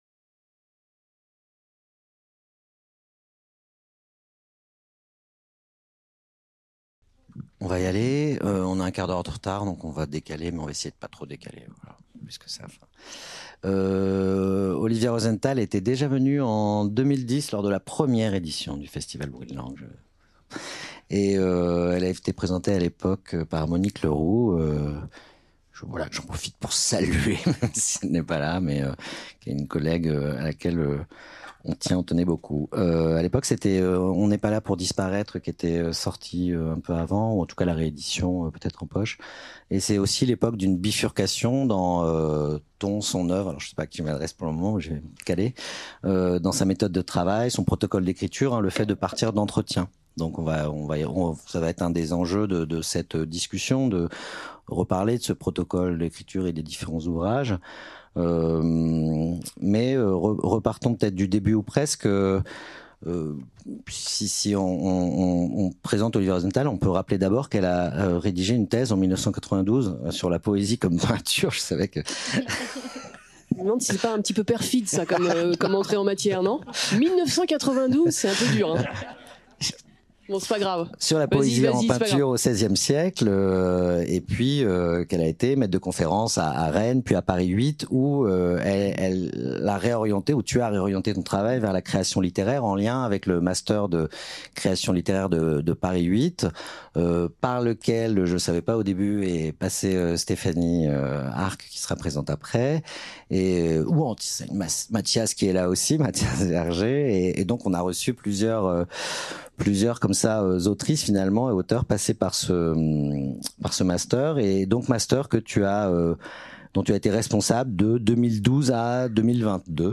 Entretien avec Olivia Rosenthal | Canal U
Entretiens avec l'autrice Olivia Rosenthal lors du festival Bruits de Langues 2023